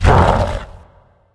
spawners_mobs_balrog_attack.4.ogg